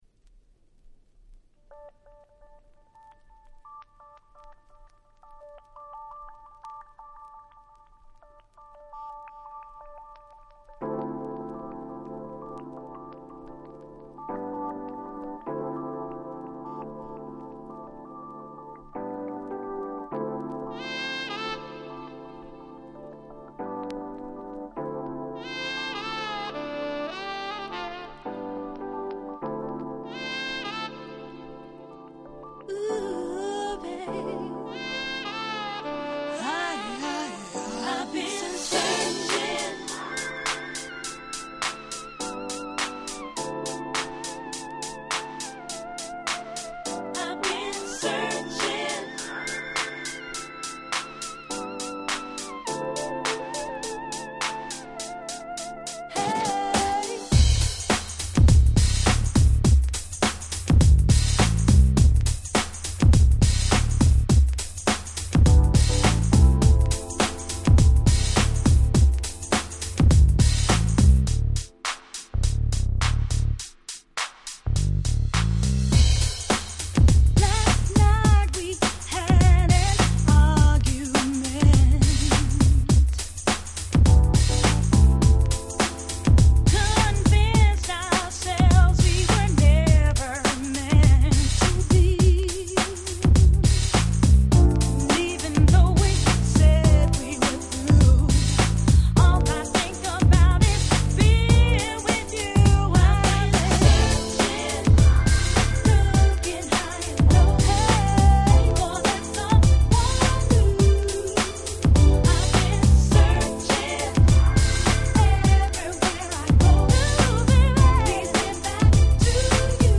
しっかりフロア対応なBeatに洗練されたMelodyと彼女のVocalが気持ち良過ぎる1曲。